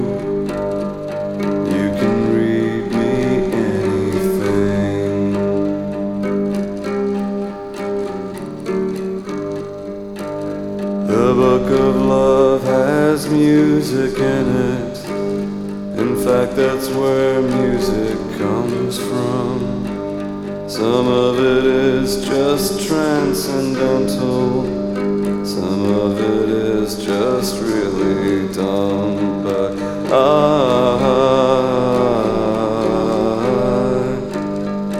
Жанр: Поп / Электроника / Рок / Альтернатива